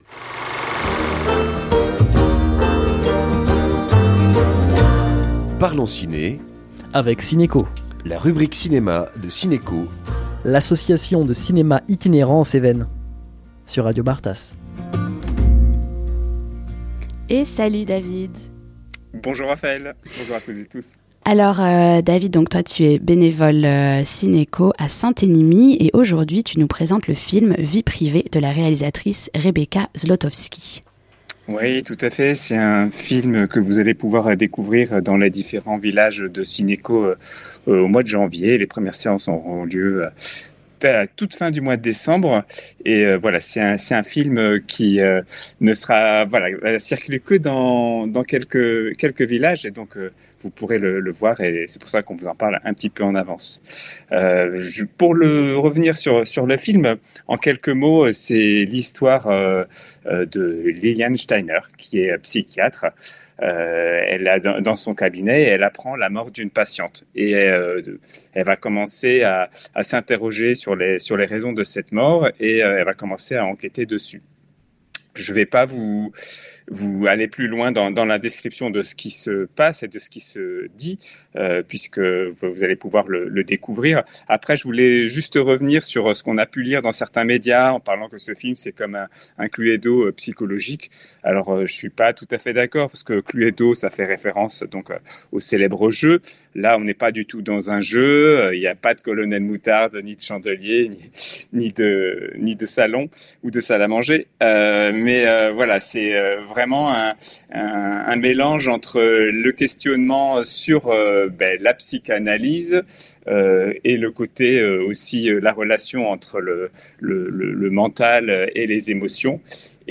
Chaque mercredi, jour des sorties cinéma, un.e salarié.e ou bénévole de Cinéco, l’association de cinéma itinérant en Cévennes, nous présente un film de leur catalogue.